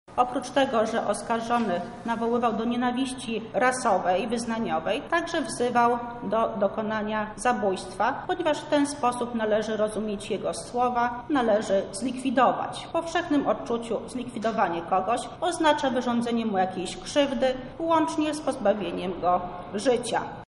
– tłumaczy w uzasadnianiu wyroku sędzia Beata Górna-Gielara.